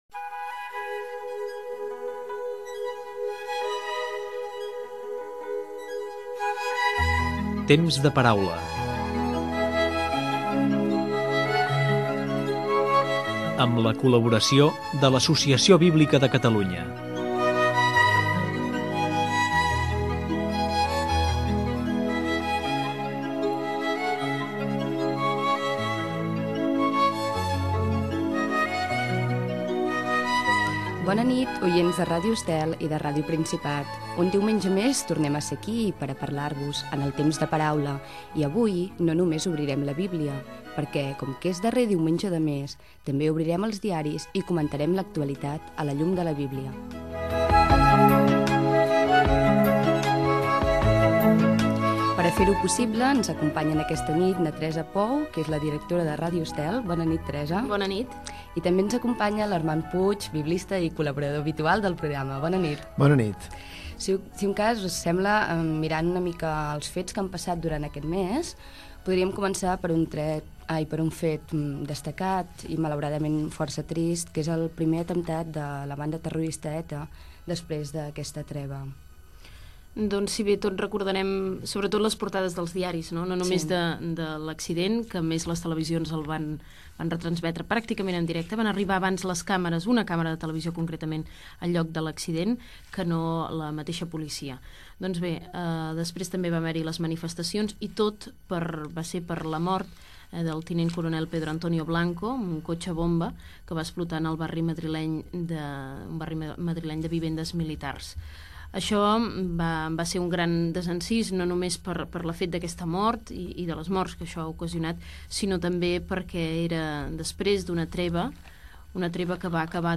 Comentari sobre l'atemptat de la banda ETA després d'un temps de treva Gènere radiofònic Religió